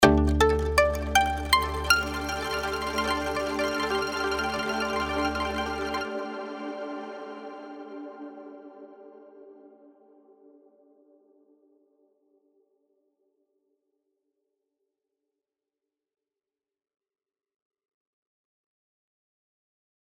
実際に「Ambient Dreams」というディレクトリーにある「Pizzicato Grains」を使用してパッチ・ミューテーション機能で得られたサウンドバリエーションとの違いを聴き比べてみてください。
以下のデモ音源「original.mp3」が元のパッチのサウンド、「mutation.mp3」がパッチ・ミューテーション機能で得られたサウンドの中の1つです。